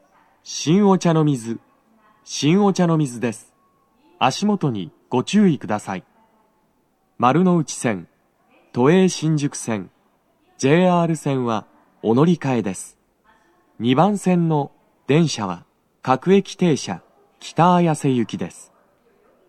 スピーカー種類 BOSE天井
足元注意喚起放送と、乗り換え放送が付帯するため、フルの難易度は高いです。
男声
到着放送1